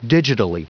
Prononciation du mot digitally en anglais (fichier audio)
Prononciation du mot : digitally